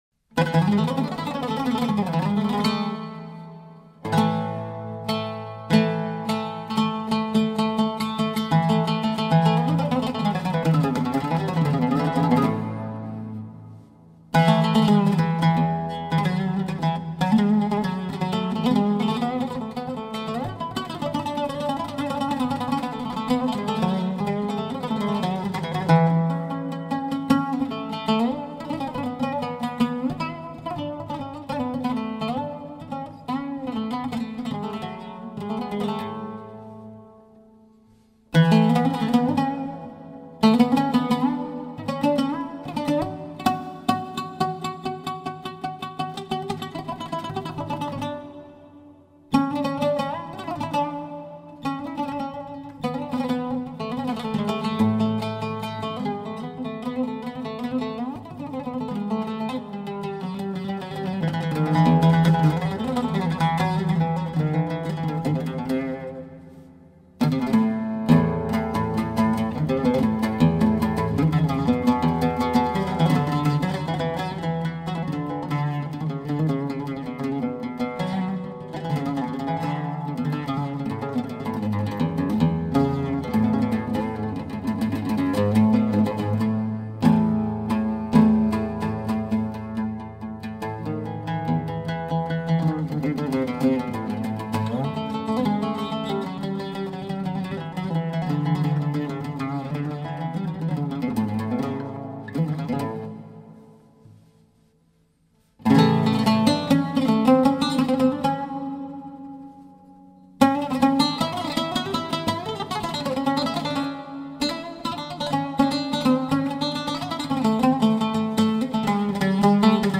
Oud taksim by Yurdal Tokcan, recorded with a Faruk Turunz Single Top Oud.